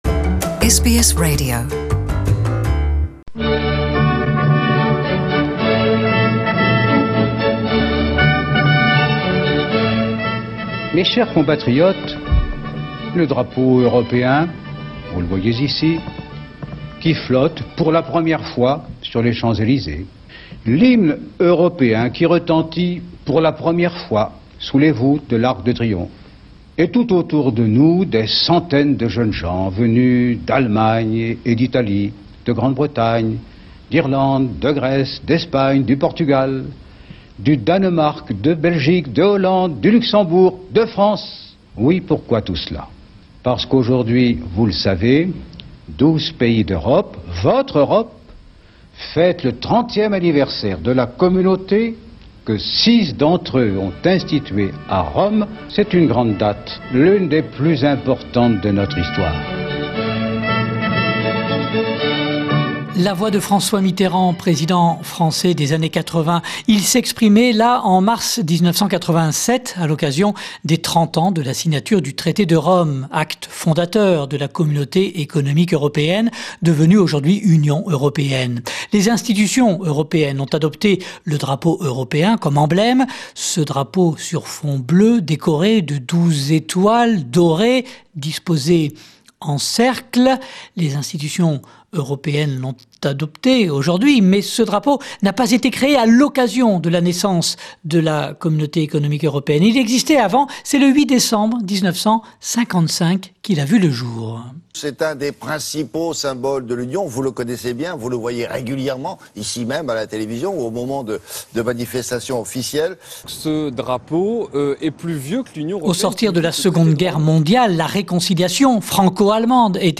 Le 8 décembre 1955, la création et l'adoption par les pays européens d'un drapeau européen. Rétrospective avec des archives sonores des différentes émissions de télévisions.